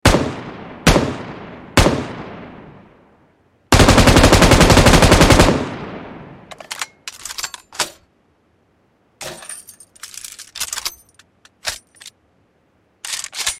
Download Weapon sound effect for free.
Weapon